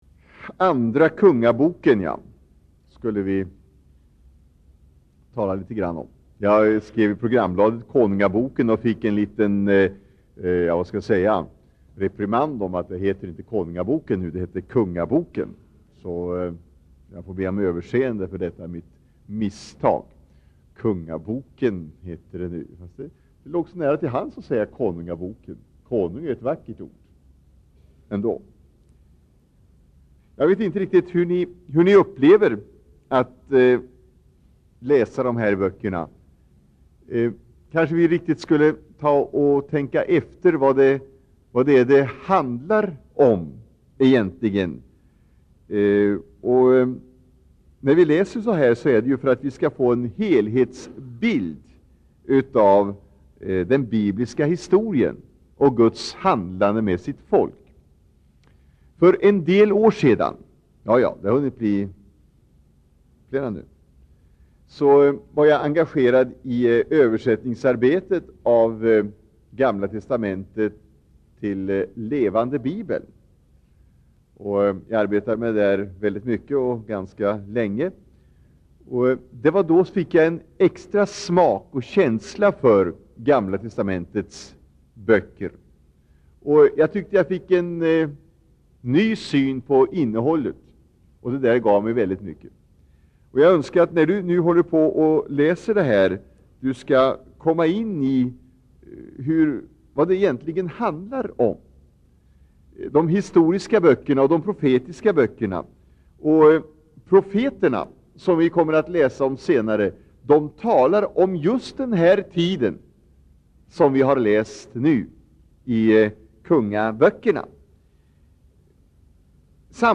Inspelad i Citykyrkan, Stockholm 1984-10-31.